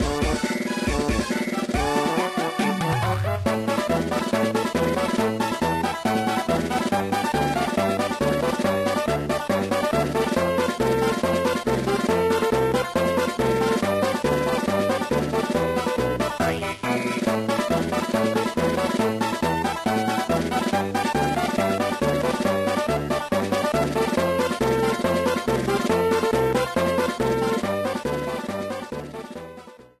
Fade-out applied